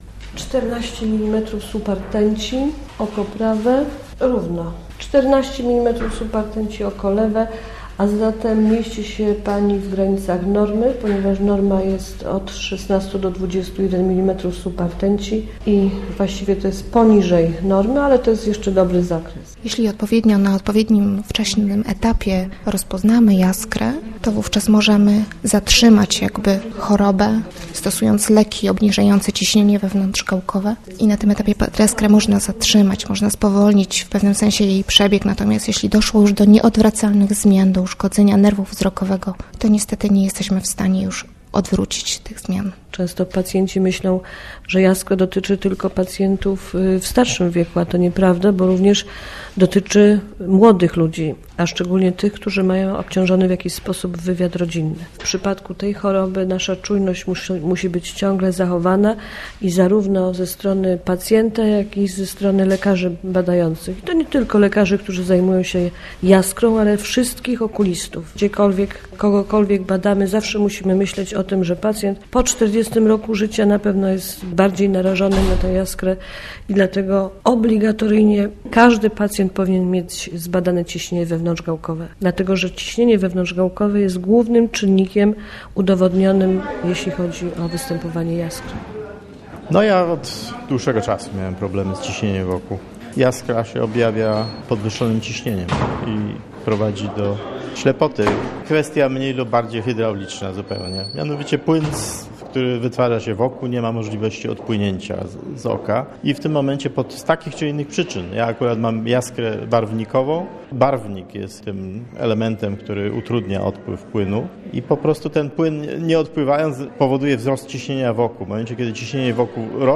Jaskra - reportaż